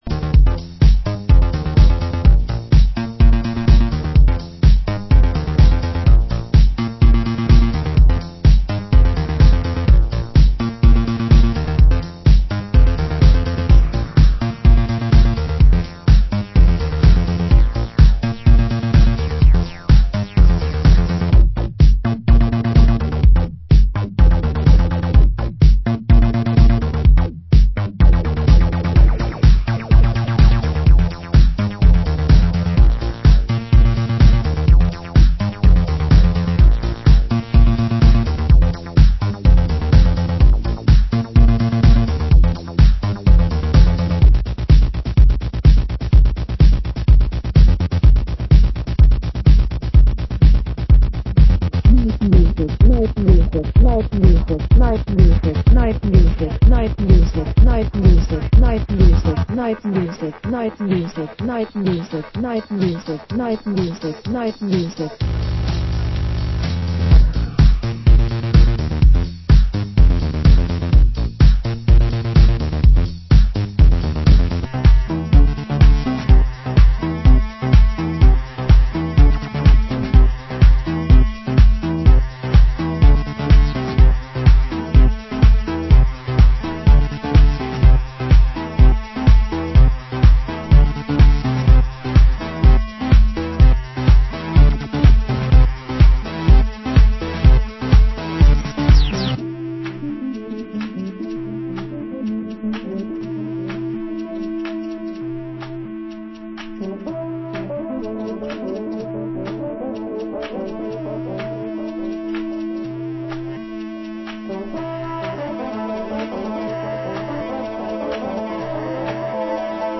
Genre: Leftfield